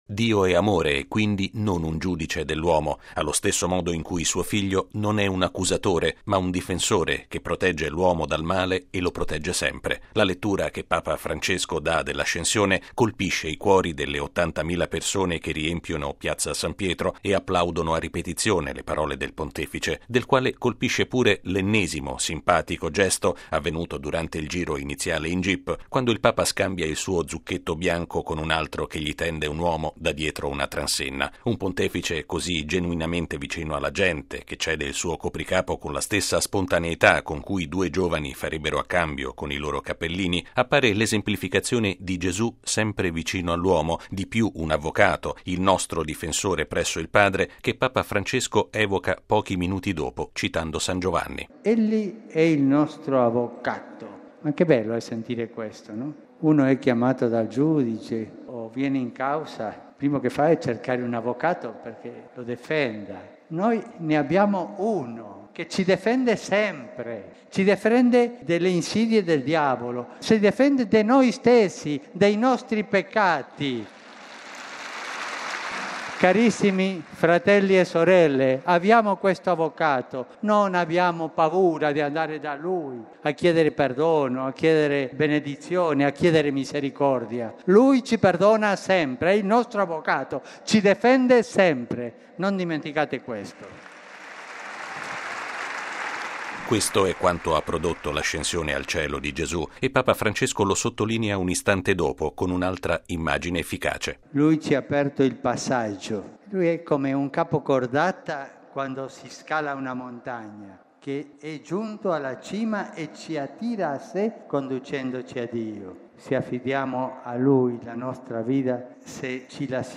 Sono alcune delle esortazioni di Papa Francesco all'udienza generale di questa mattina in Piazza San Pietro. Di fronte a circa 80 mila fedeli, il Pontefice ha parlato dell’Ascensione di Gesù affermando che con essa l’uomo ha guadagnato un “avvocato” presso Dio.
La lettura che Papa Francesco dà dell’Ascensione colpisce i cuori delle 80 mila persone che riempiono Piazza San Pietro e applaudono a ripetizione le parole del Pontefice.